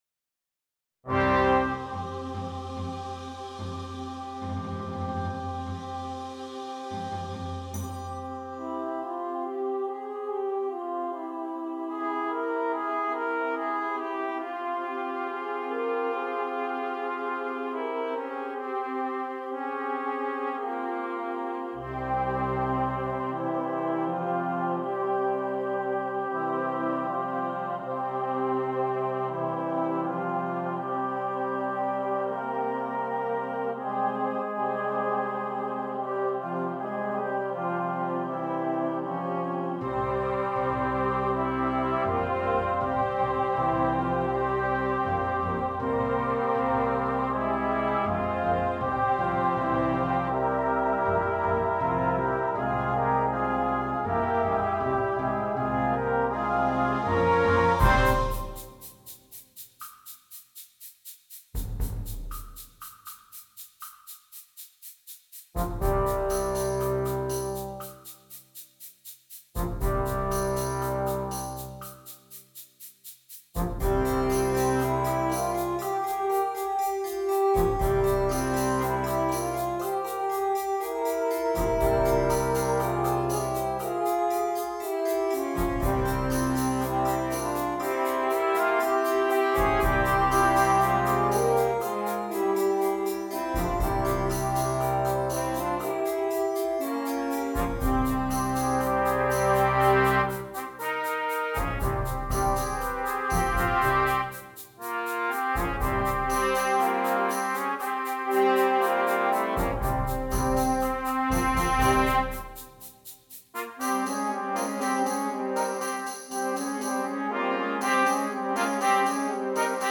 Voicing: 9 Br/Perc